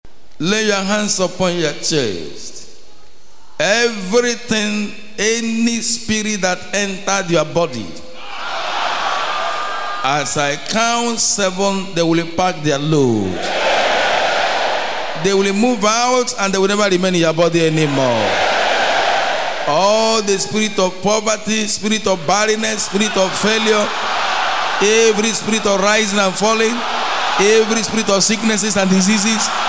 prayer31.mp3